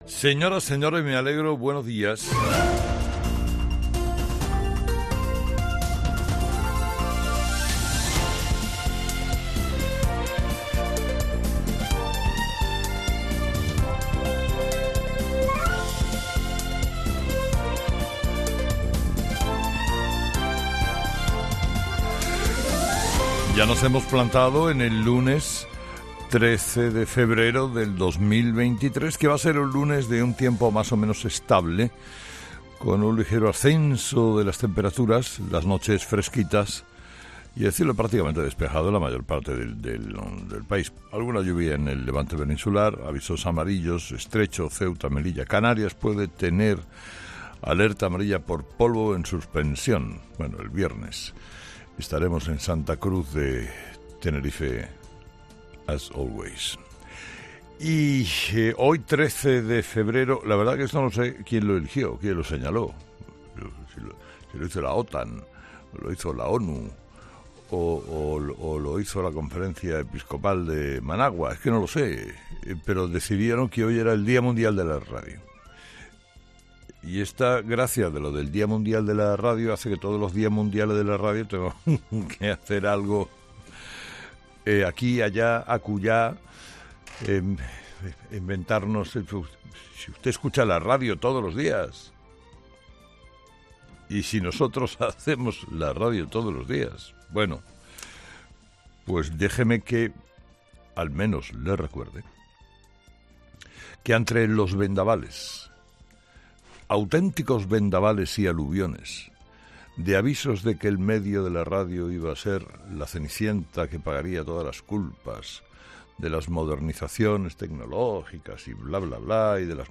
Carlos Herrera repasa los principales titulares que marcarán la actualidad de este lunes 13 de febrero en nuestro país
Carlos Herrera, director y presentador de ' Herrera en COPE ', comienza el programa de este luens analizando las principales claves de la jornada, que pasan, entre otros asuntos, por la manifestación convocada en defensa de la sanidad pública en diferentes lugares de España, centrando el foco sobre la de Madrid .